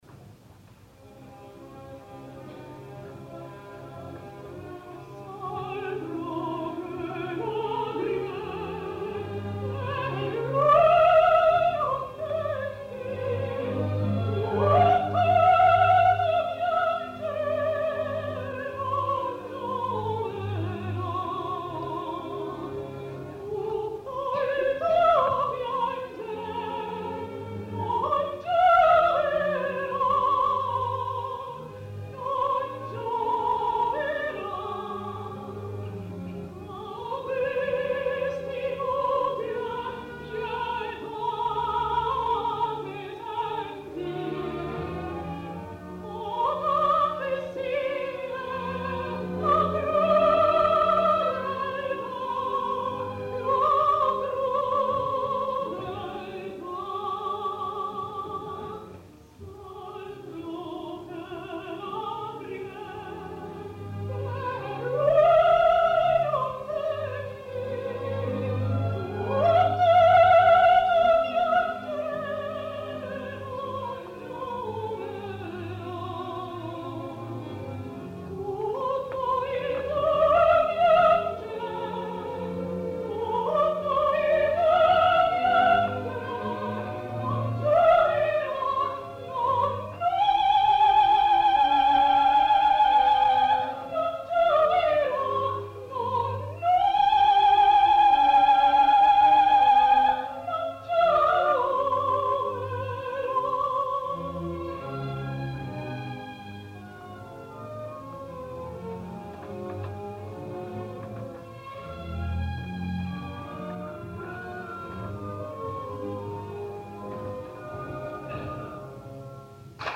Lluny de les gravacions més conegudes, us vull portar en aquest sentit homenatge, mostres de la versatilitat, musicalitat, adequació estil·lística i gust extrem de la gran mezzo, cantant l’ària de la Servilia de La Clemenza di Tito de Mozart, tal i com va cantar-la a la Piccola Scala el 1 de febrer de 1966 sota la direcció de Nino Sanzogno.